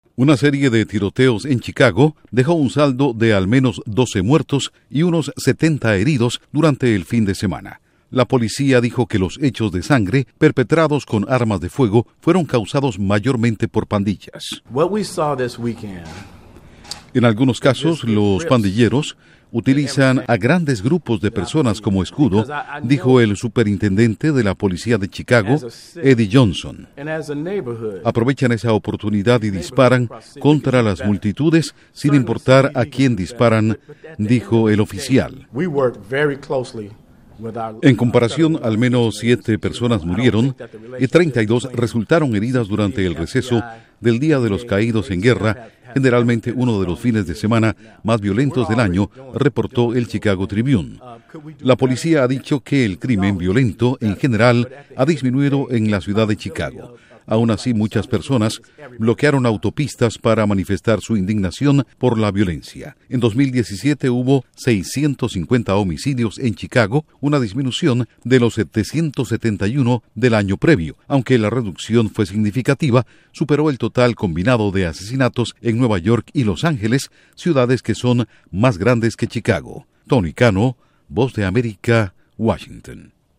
Al menos 12 muertos y 70 heridos durante violento fin de semana en Chicago. Informa desde la Voz de América en Washington